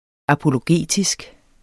apologetisk adjektiv Bøjning -, -e Udtale [ apoloˈgeˀtisg ] Oprindelse jævnfør apologi Betydninger 1.